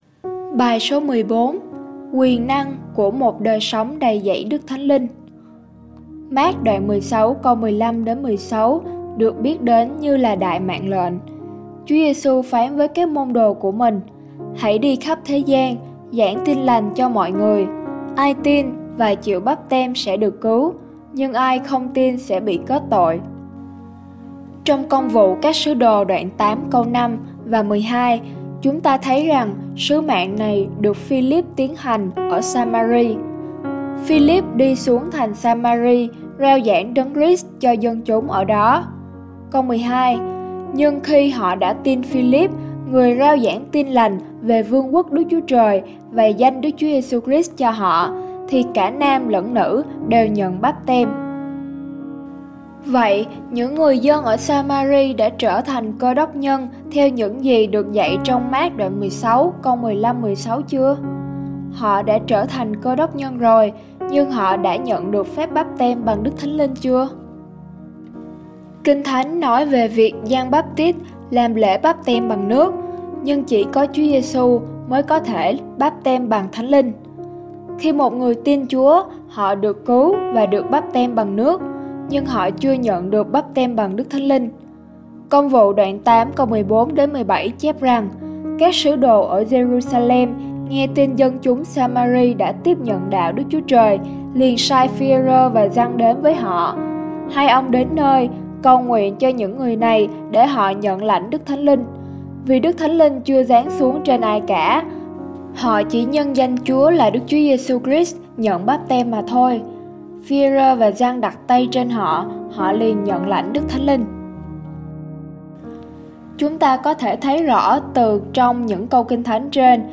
BÀI HỌC